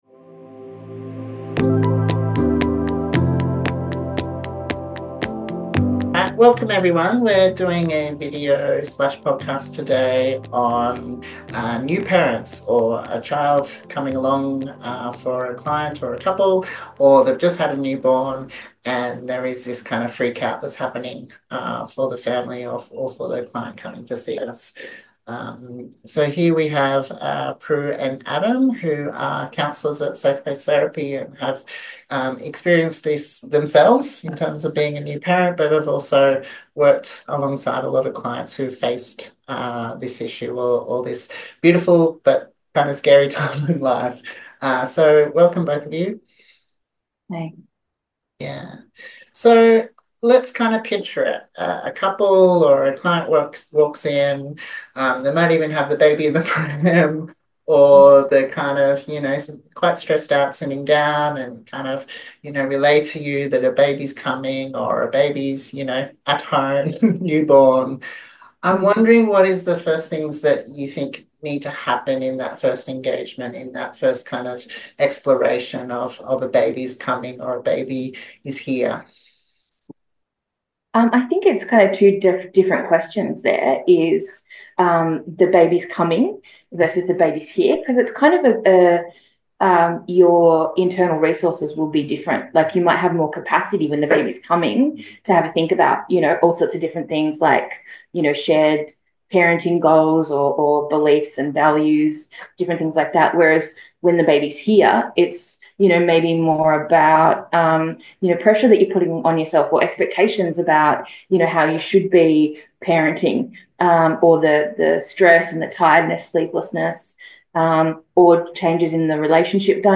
Therapists talk about how to cope with parental stress – before and after baby arrives.